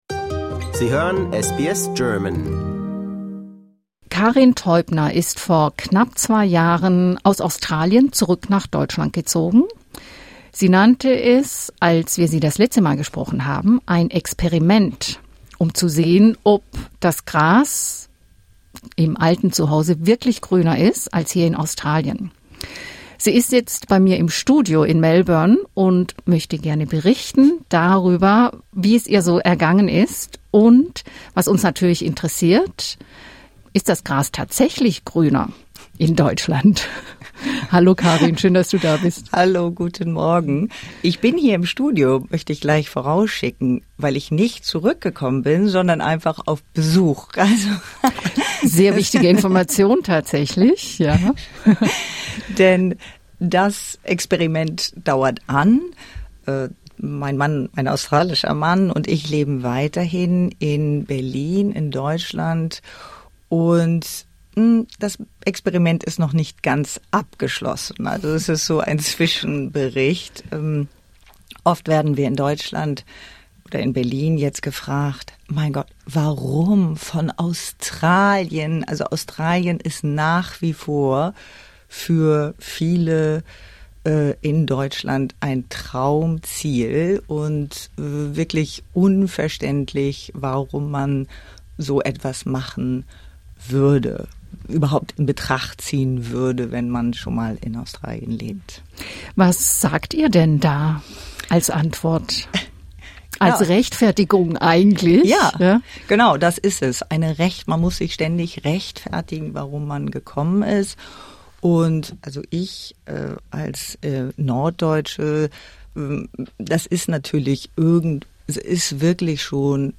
For more stories, interviews and news from SBS German, explore our podcast collection here.